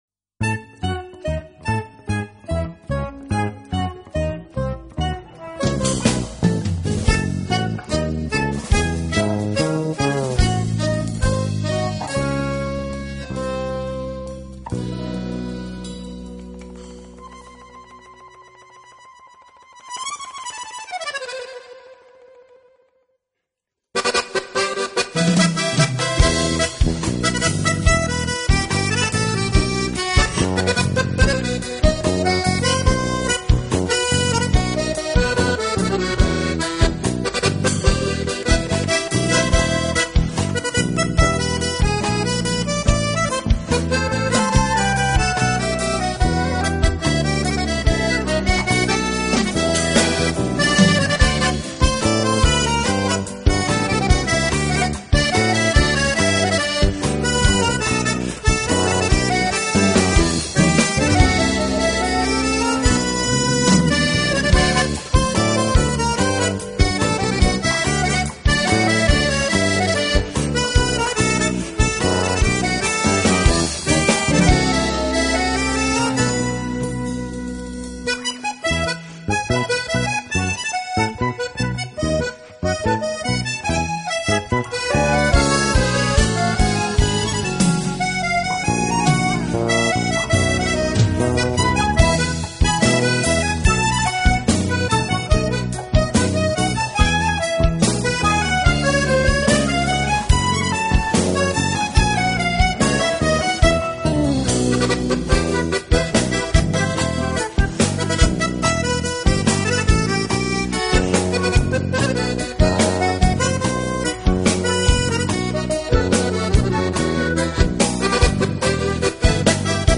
南美手风琴大师精彩演绎十五首风格独特的巴西音乐，旋律优美流畅，节奏 轻快活泼。